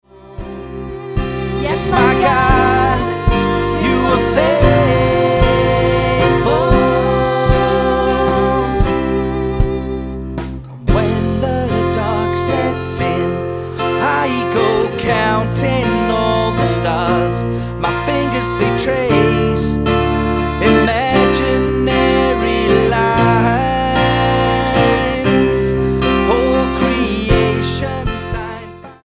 STYLE: Pop
picks up the pace